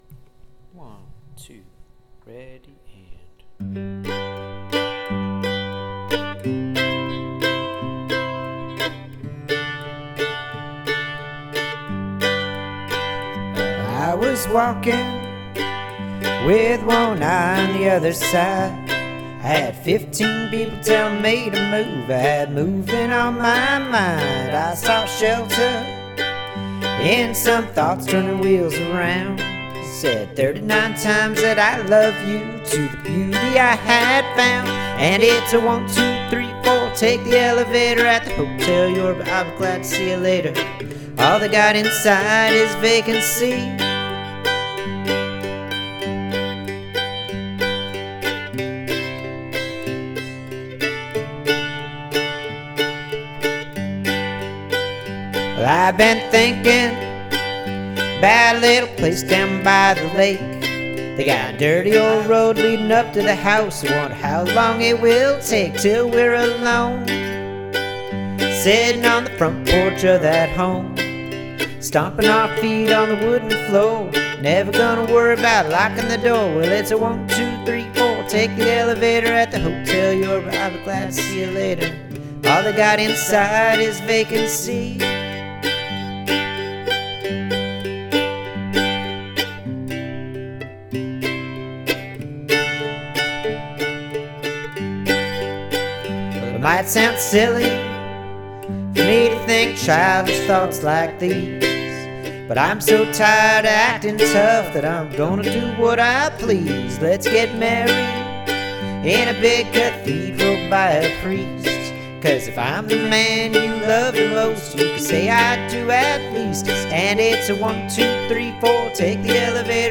Practice on my norns-powered digital four track
cover (including mistakes, heh